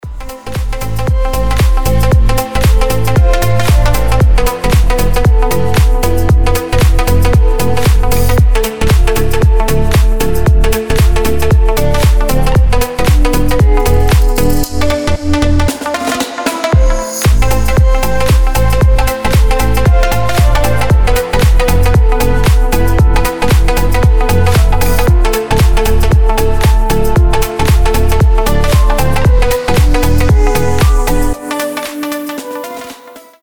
ритмичные
deep house
мелодичные
Electronic
без слов
Ритмичный deep'чик